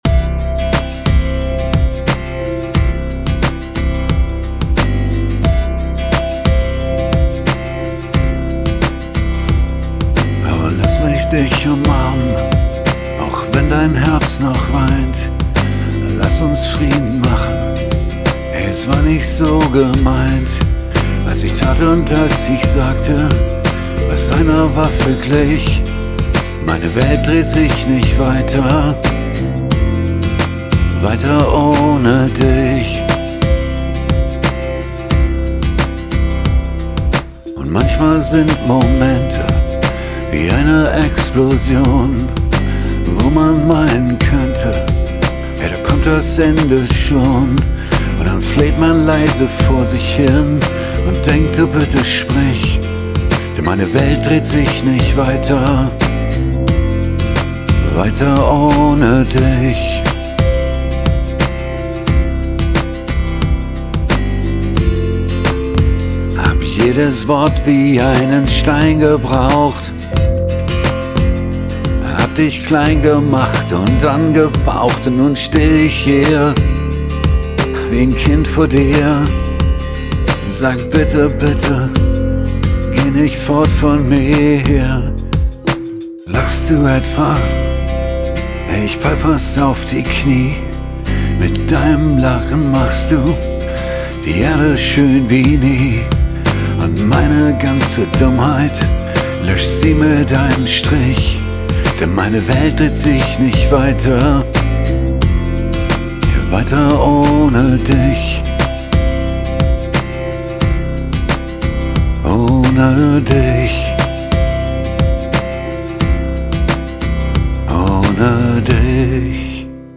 Heute morgen mal schnell gebastelt.